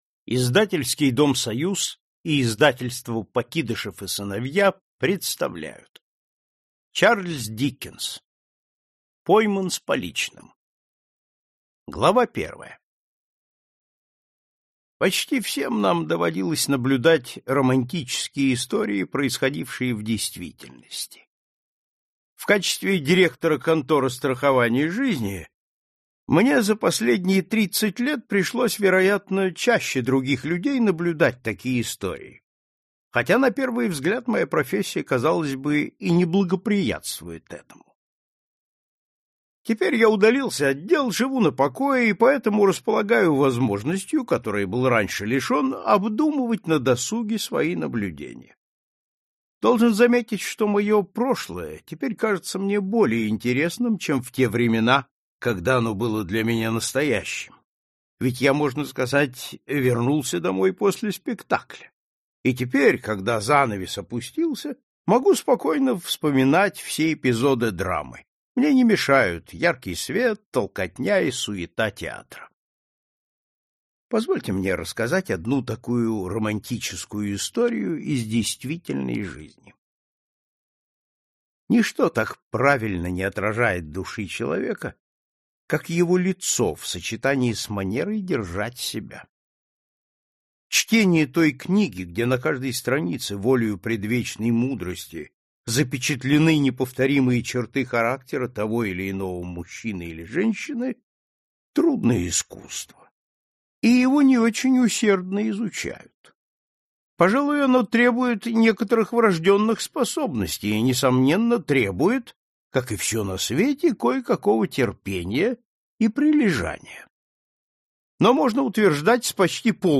Аудиокнига Классика зарубежного детективного рассказа 3 | Библиотека аудиокниг